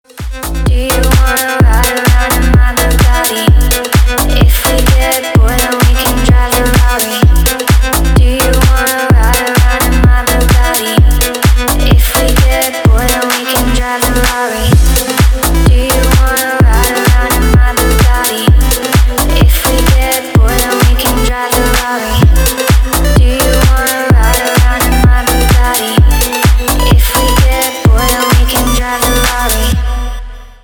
• Качество: 320, Stereo
deep house
Electronic
EDM
чувственные
танцевальная музыка
Стиль: deep house